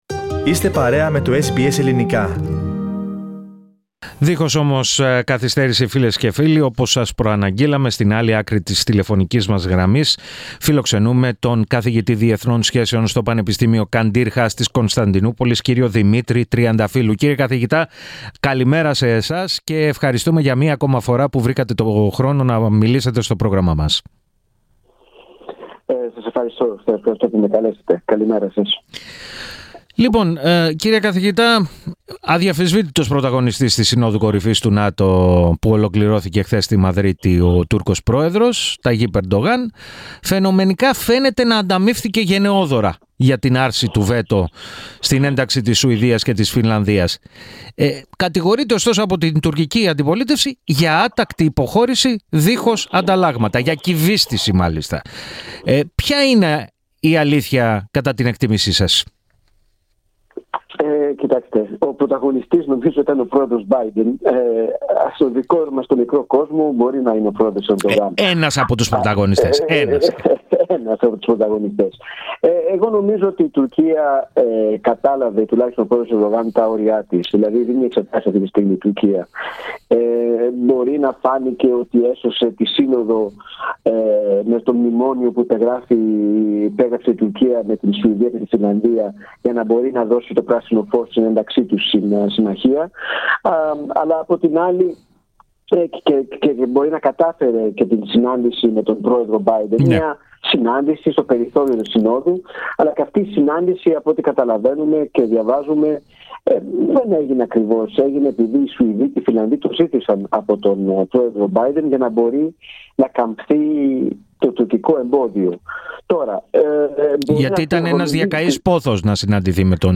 μίλησε στο Ελληνικό Πρόγραμμα της ραδιοφωνίας SBS